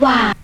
VOX SHORTS-1 0007.wav